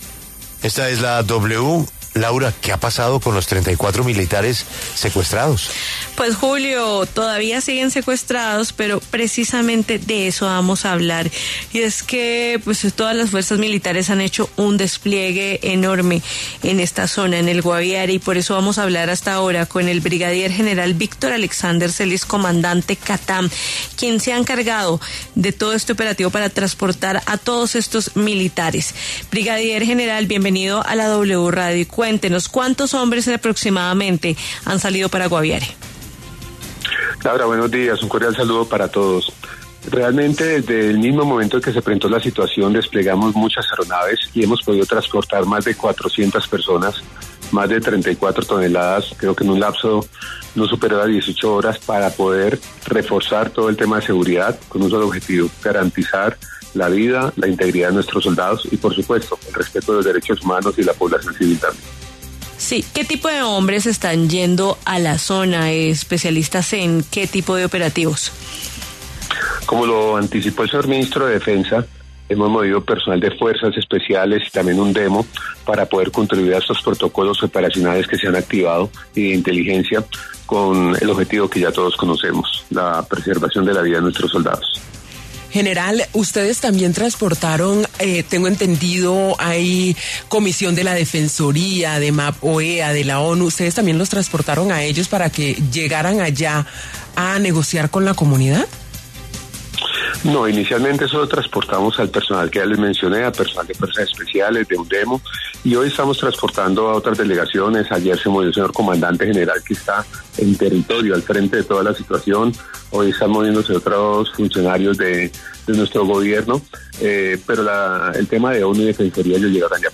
El brigadier general Víctor Celis Herrera, comandante de CATAM, se refirió en La W al secuestro de 33 militares en Guaviare.